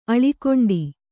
♪ aḷikoṇḍi